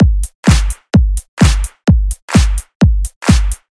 炽热的EDM鼓声
描述：只是一个基本的电子音乐节拍：P
Tag: 128 bpm Electro Loops Drum Loops 647.01 KB wav Key : Unknown